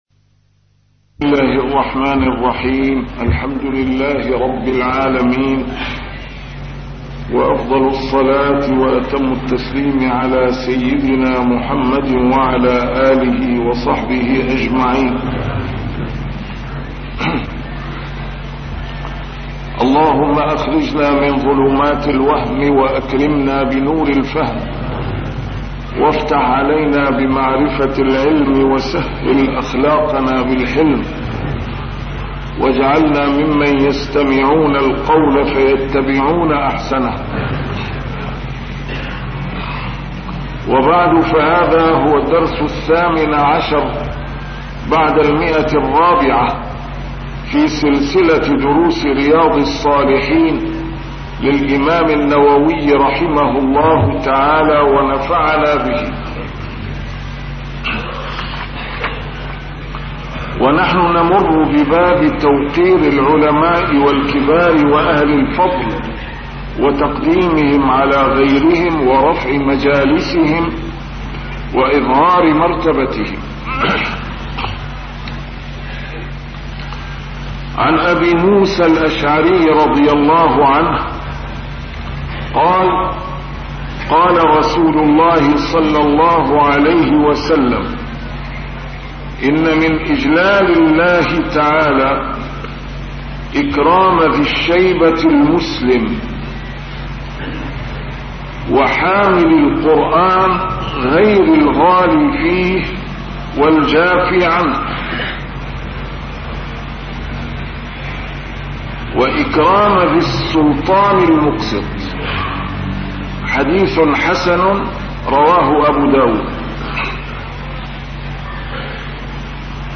شرح كتاب رياض الصالحين - A MARTYR SCHOLAR: IMAM MUHAMMAD SAEED RAMADAN AL-BOUTI - الدروس العلمية - علوم الحديث الشريف - 418- شرح رياض الصالحين: توقير العلماء